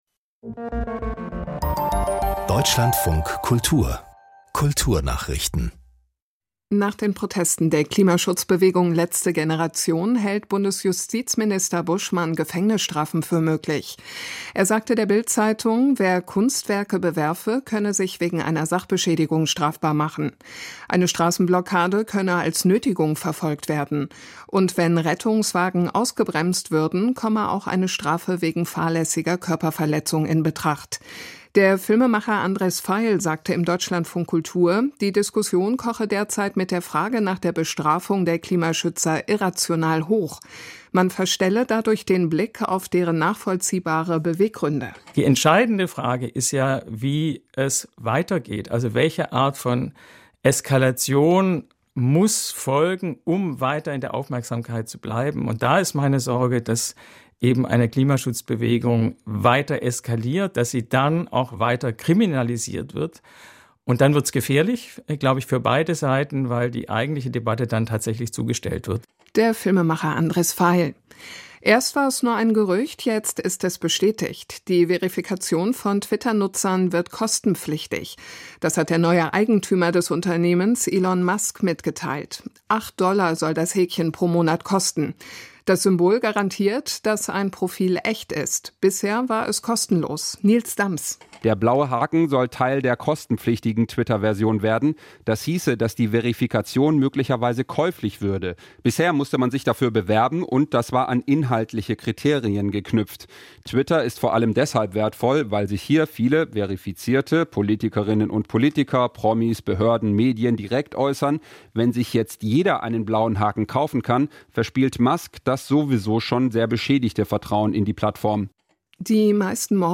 Kulturnachrichten - 02.11.2022